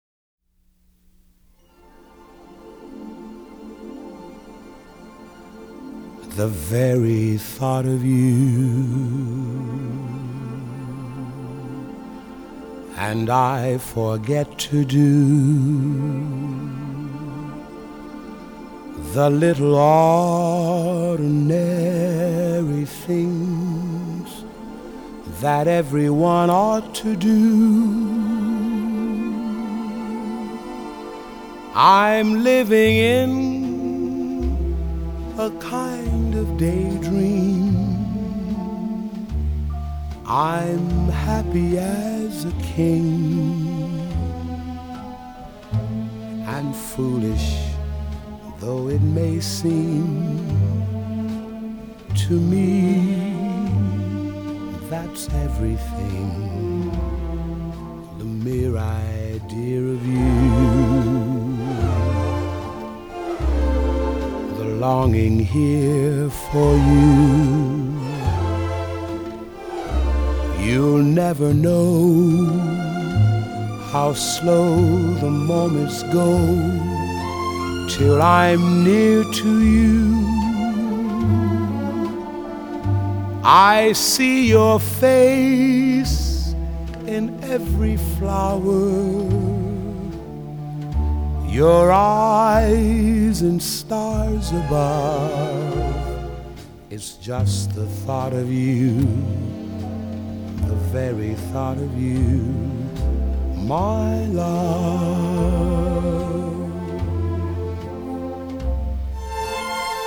用深情無比的嗓音融化您的心！
採用原始類比母帶以最高音質之 45 轉 LP 復刻！